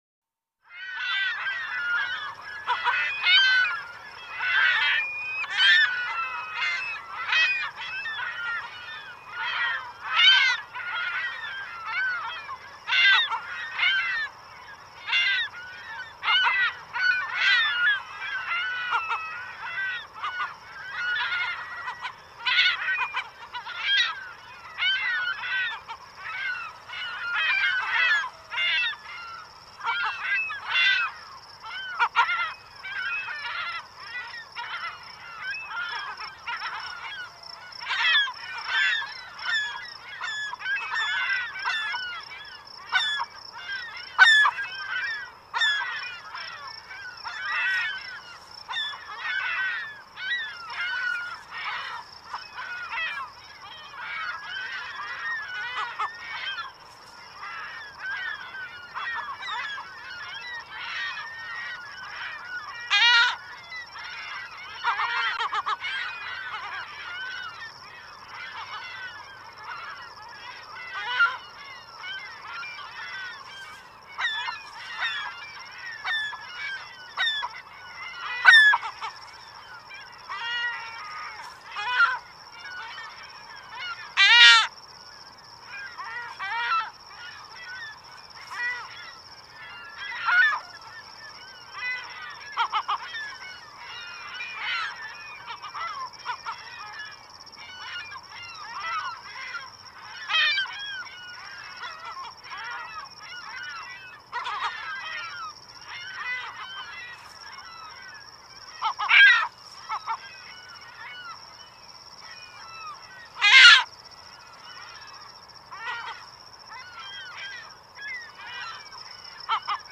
Gull | Sneak On The Lot
Gull, California Squawking. A Large Group Of Gulls Squawking As If Fighting For Food. Ocean Ambience In The Background.